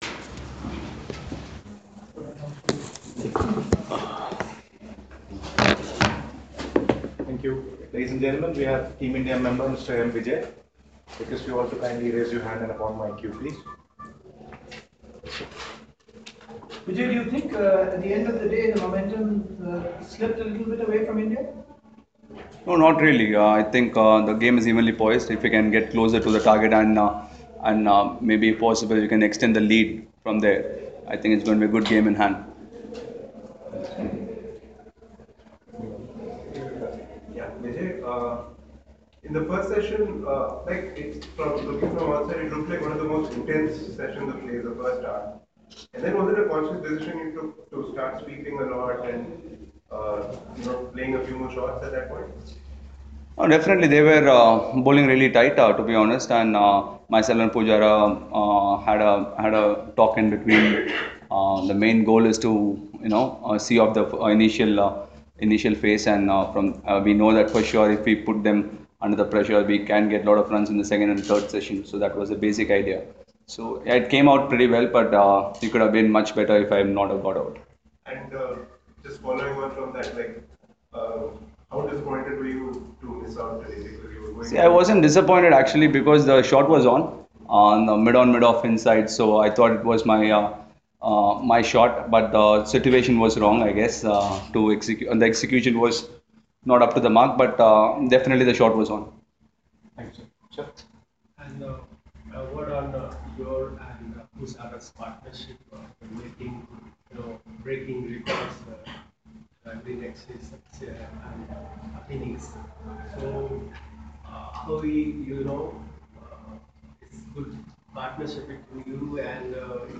LISTEN: Murali Vijay speaks after Day 3 of the Ranchi Test